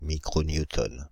Ääntäminen
France (Île-de-France): IPA: /mi.kʁo.nju.tɔn/